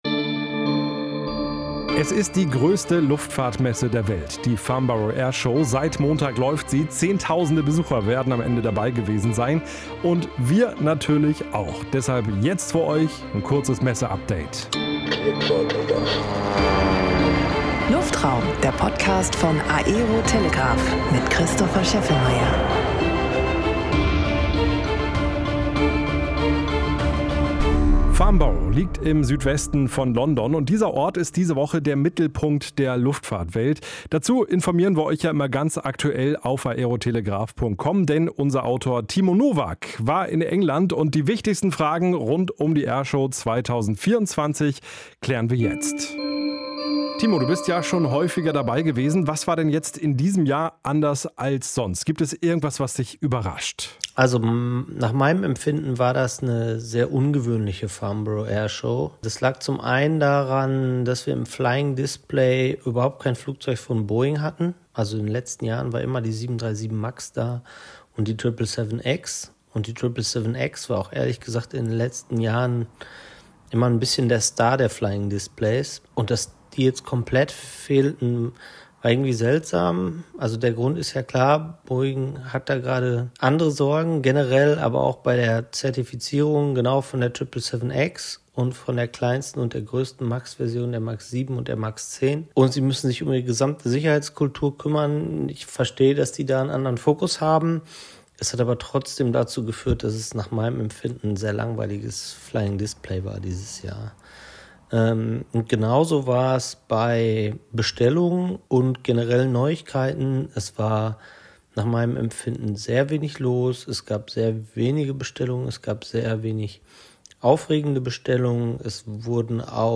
Im Talk erfahrt ihr in dieser Folge was die großen Themen der Messe sind.